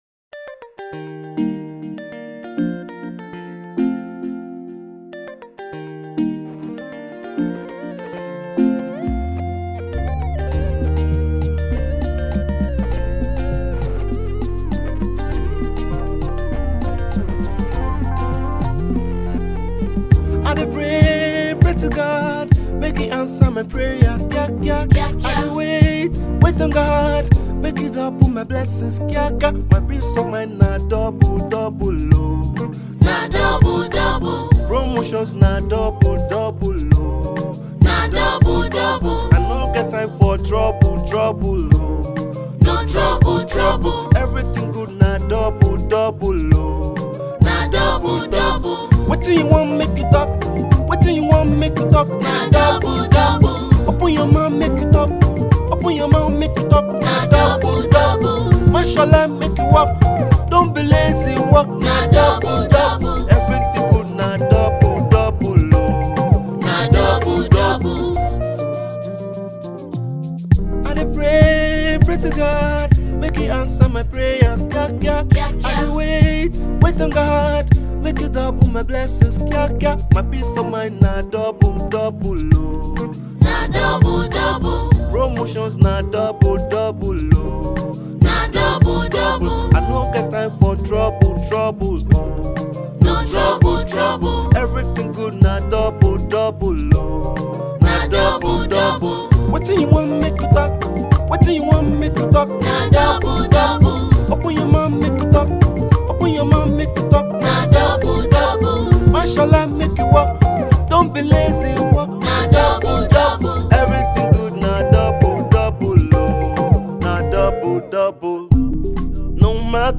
gospel-inspirational track